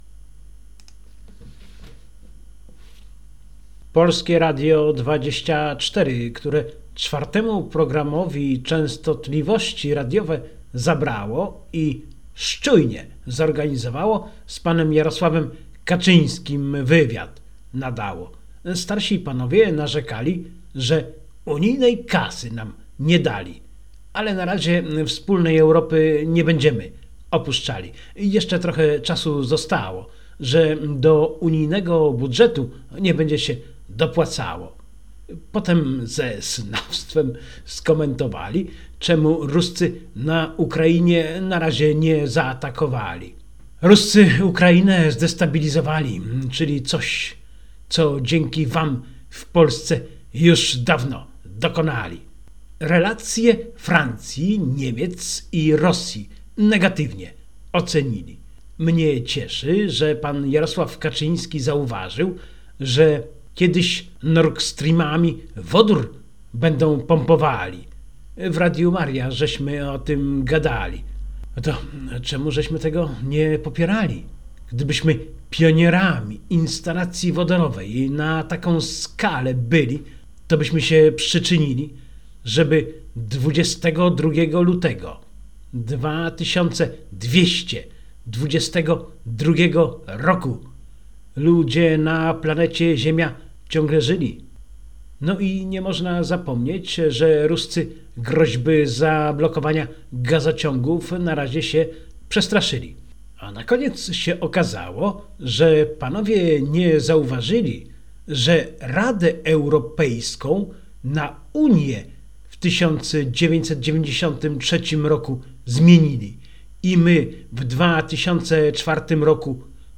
A18.02.-Wywiad.mp3